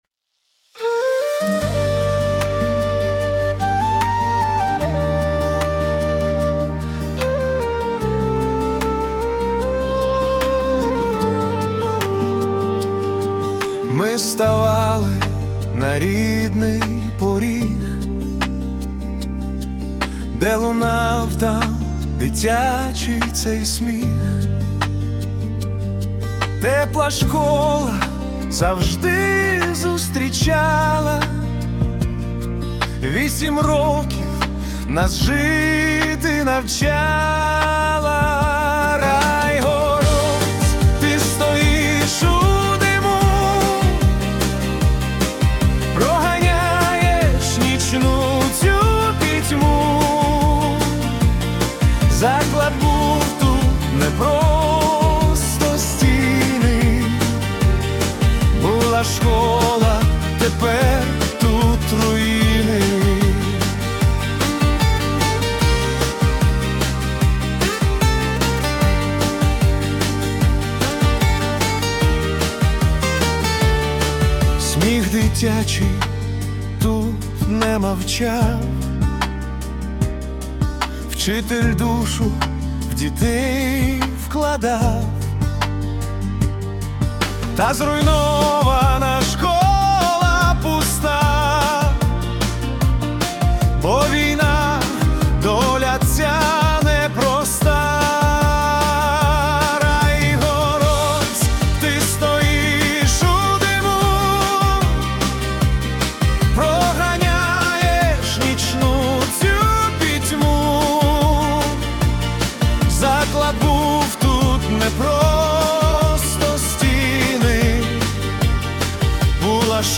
Балада про війну